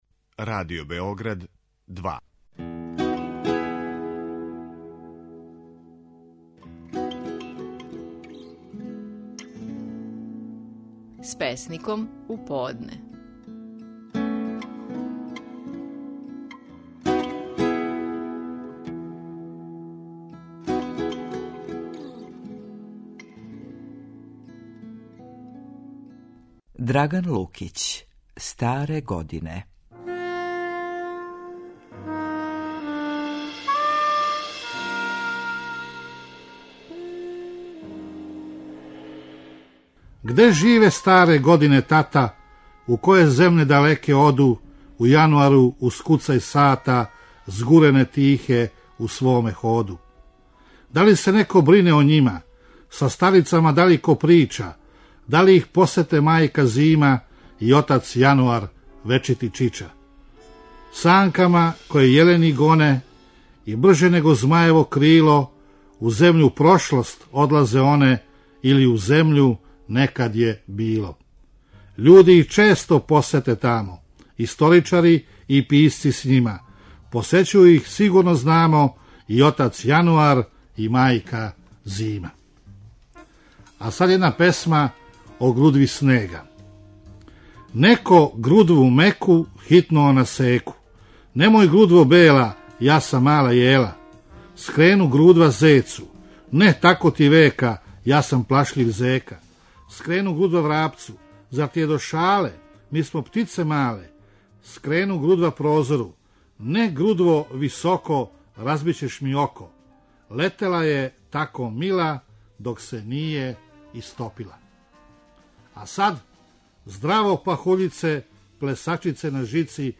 Наши најпознатији песници говоре своје стихове
Драган Лукић говори своју песму „Старе године".